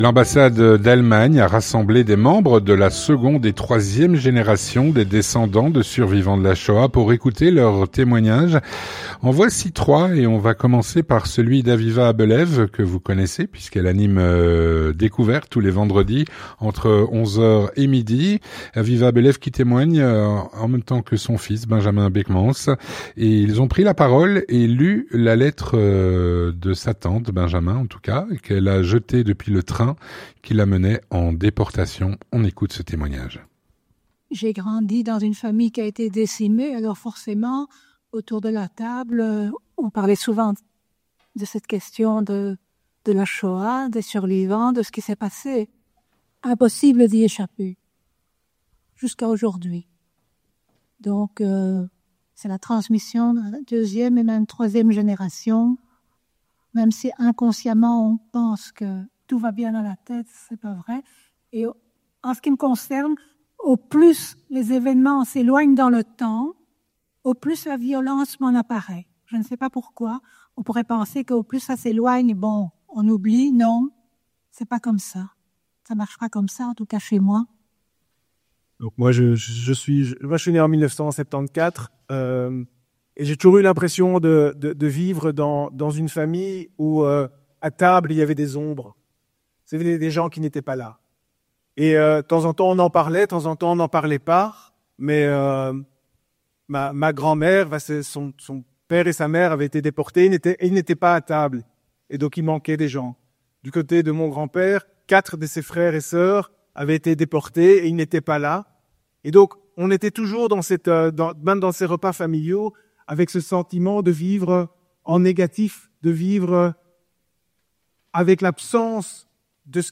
Témoignage - L’ambassade d’Allemagne a rassemblé des membres de la seconde et troisième génération des descendants de survivants de la Shoah.